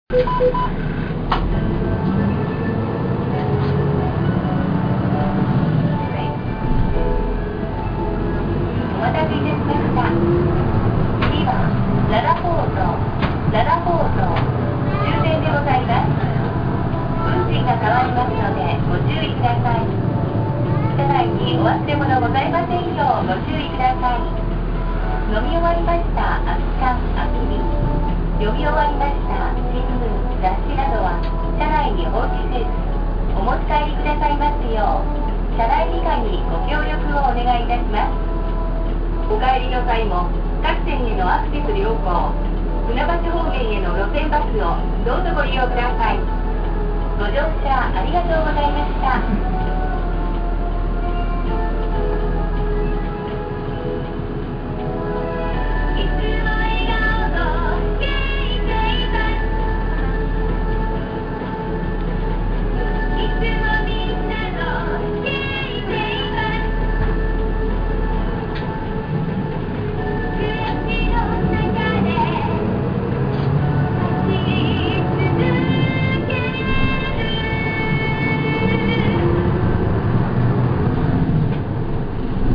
次が終点の場合には原曲のインストが放送と共に流れるのが大きな特徴。